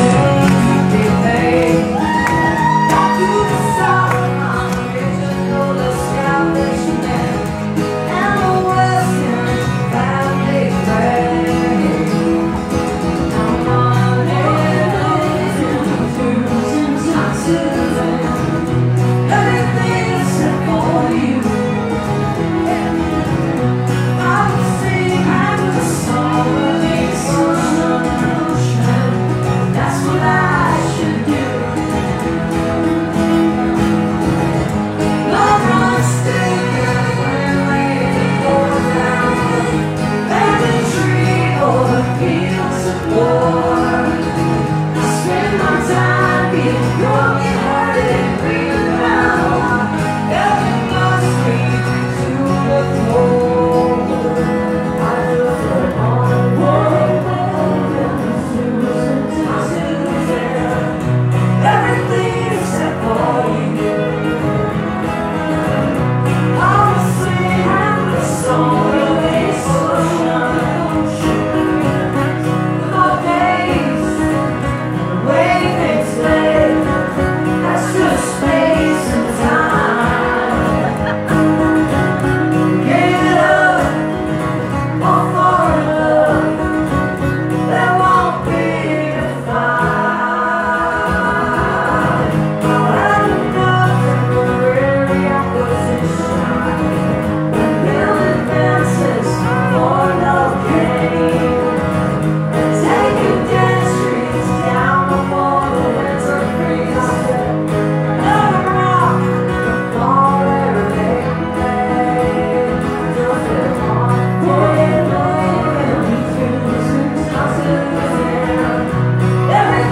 (captured from facebook live stream)